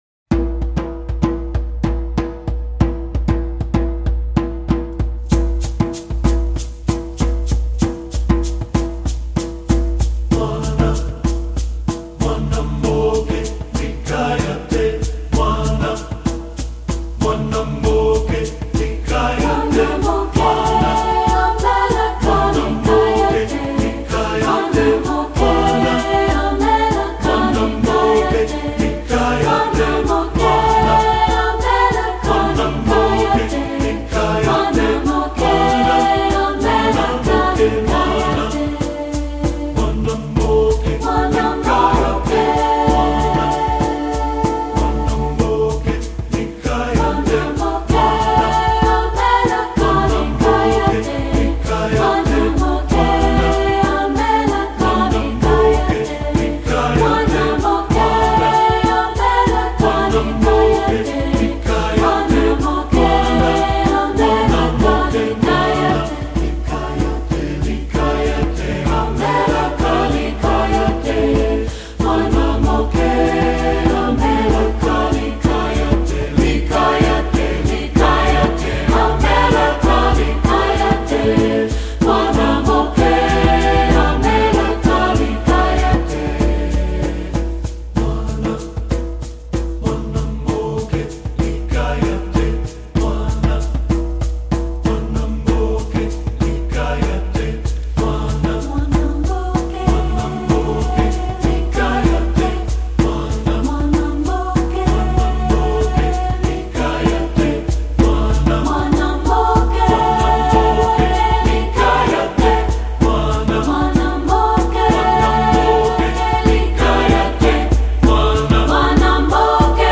Choral Multicultural
SATB A Cap